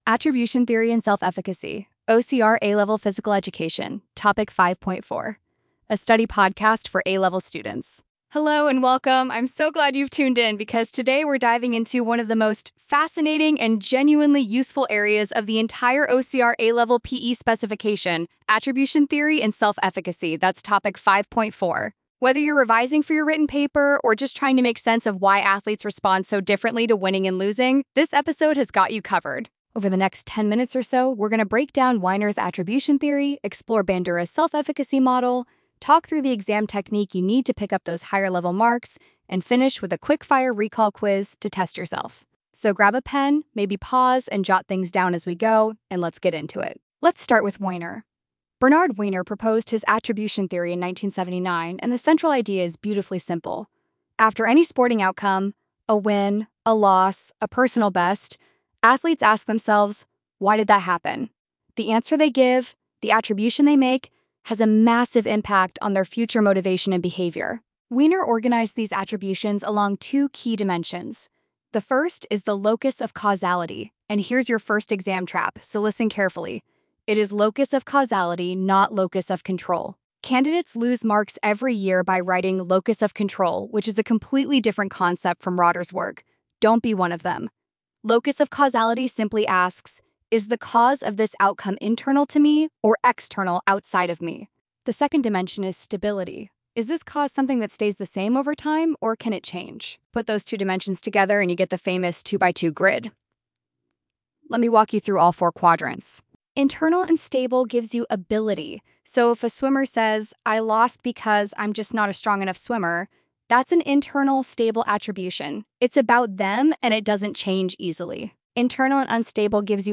attribution_theory_self_efficacy_podcast.mp3